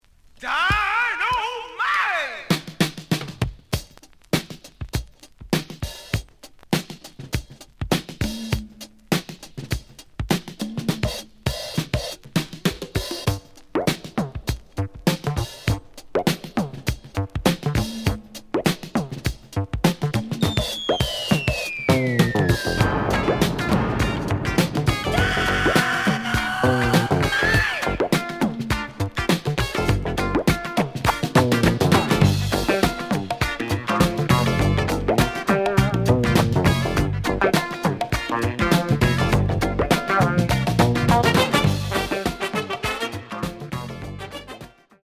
試聴は実際のレコードから録音しています。
●Genre: Funk, 70's Funk